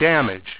w1_damage.wav